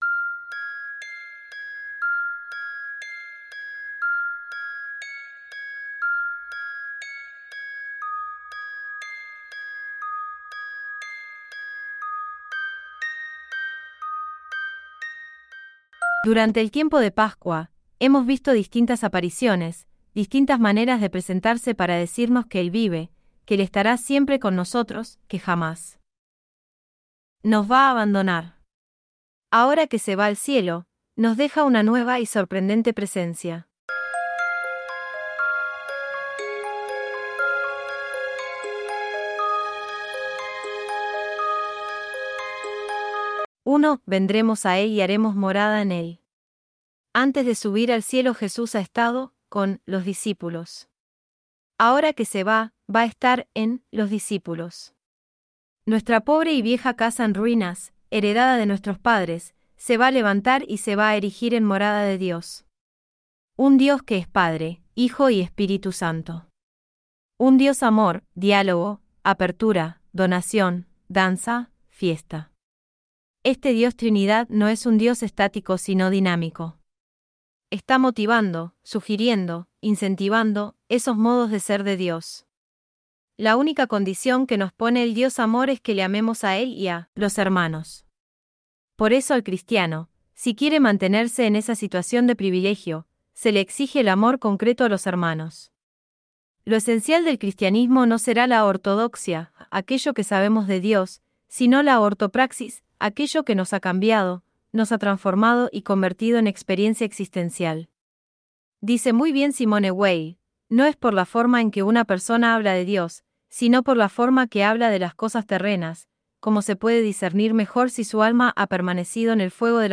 MEDITACIÓN DEL EVANGELIO EN AUDIO